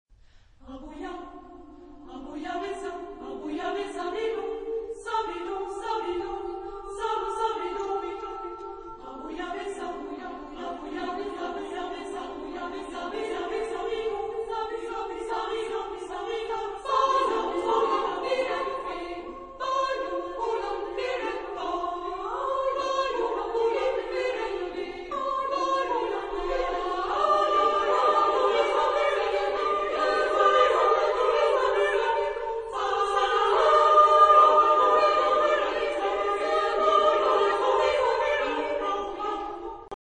Genre-Style-Forme : Cycle ; Pièce vocale ; Profane
Type de choeur : SSSSAAAA  (8 voix égales de femmes )
Tonalité : libre
Réf. discographique : 7. Deutscher Chorwettbewerb 2006 Kiel
Consultable sous : 20ème Profane Acappella